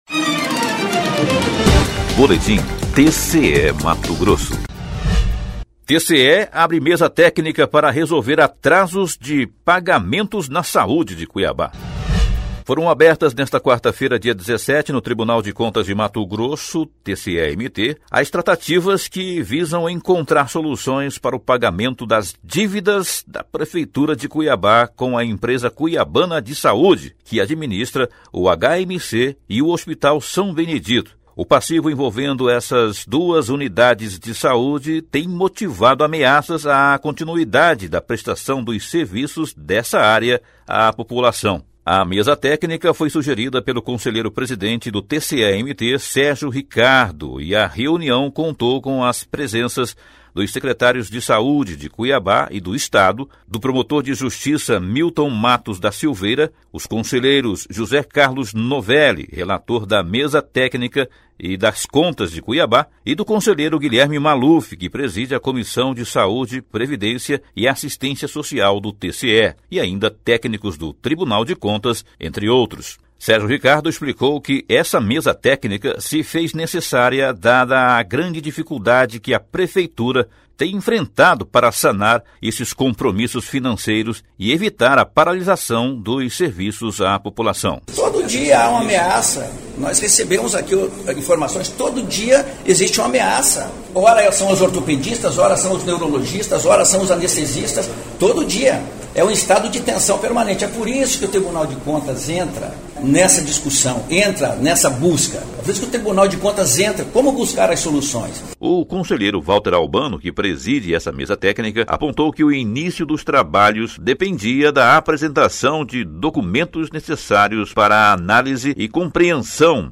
Sonora: Sérgio Ricardo – conselheiro-presidente do TCE-MT
Sonora: Valter Albano – conselheiro presidente da CPNjur
Sonora :  Deiver Teixeira -  secretário de saúde de Cuiabá